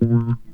Bass_Stab_05.wav